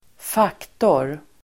Uttal: [²f'ak:tor]